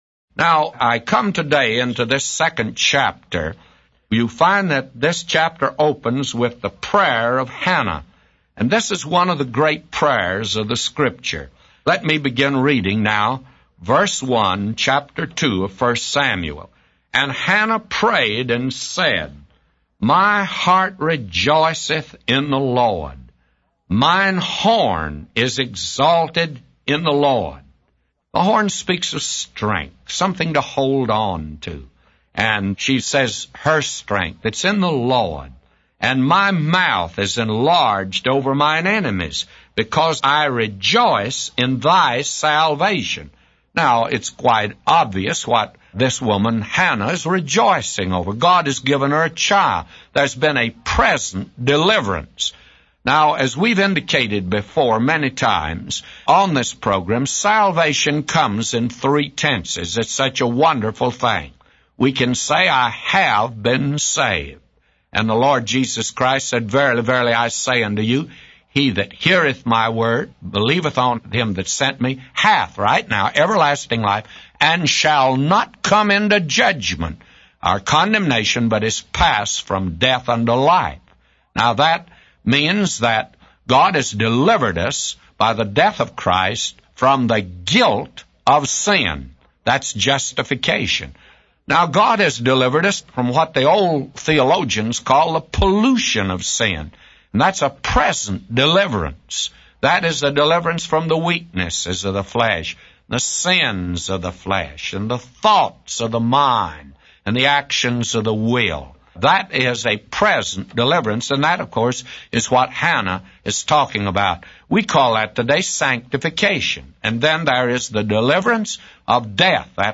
A Commentary By J Vernon MCgee For 1 Samuel 2:1-999